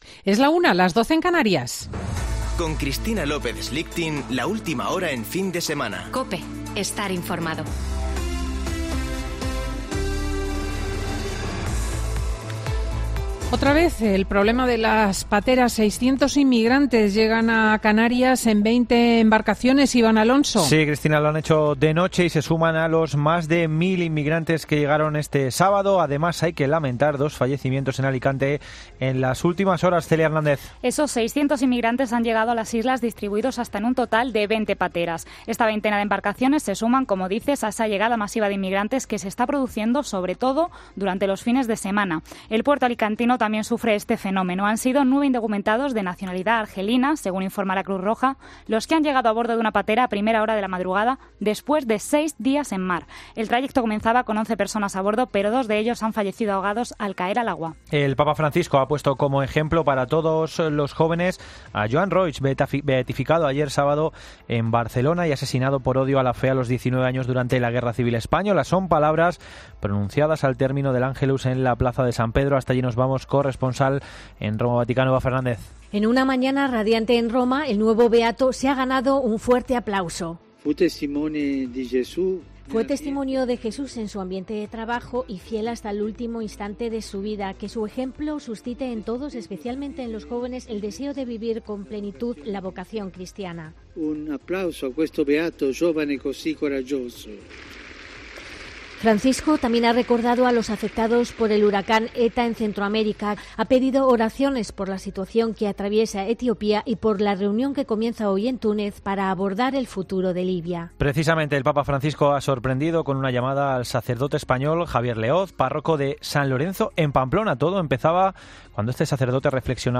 Boletín de noticias de COPE del 8 de noviembre de 2020 a las 13.00 horas